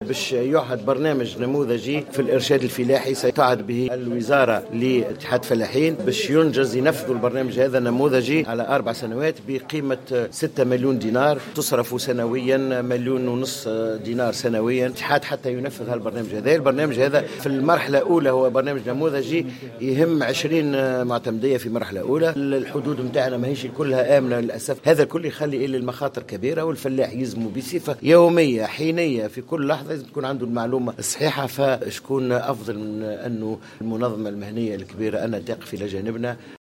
وزير الفلاحة